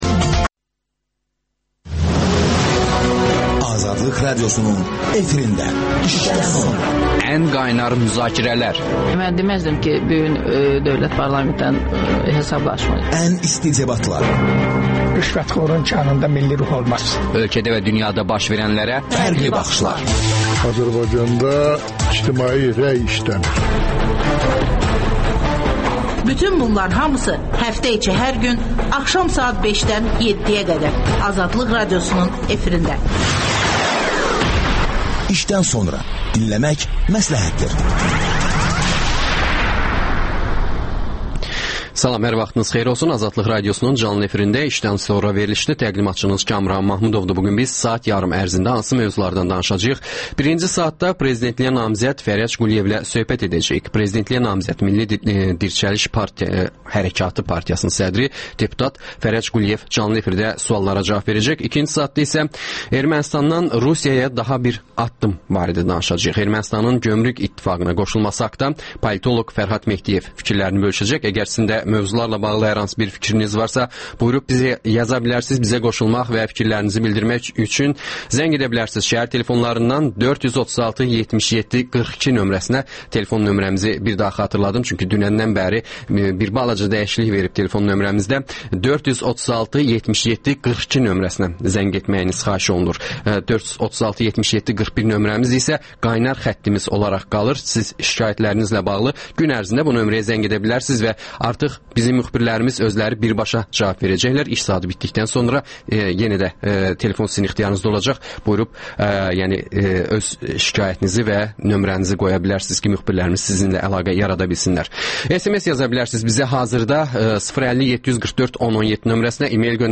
Prezidentliyə namizəd, Milli Dirçəliş Hərəkatı Partiyasının sədri, deputat Fərəc Quliyev canlı efirdə suallara cavab verir.